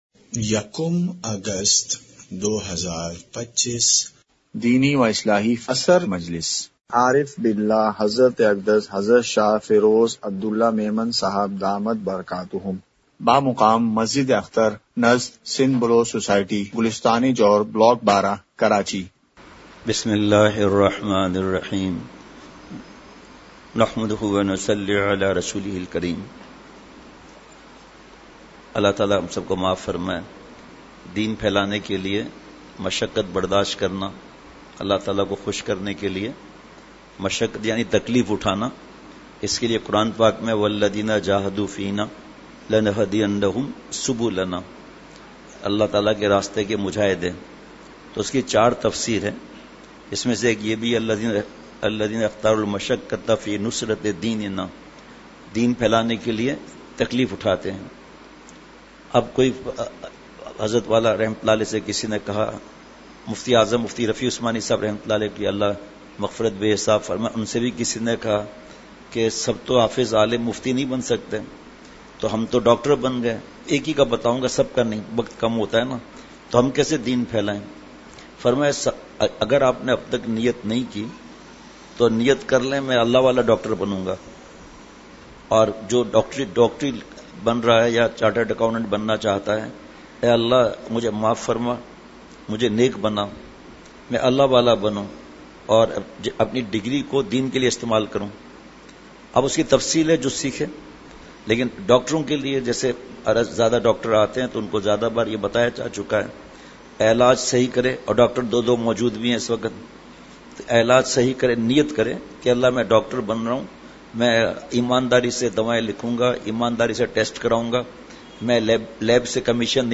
اصلاحی مجلس
مقام:مسجد اختر نزد سندھ بلوچ سوسائٹی گلستانِ جوہر کراچی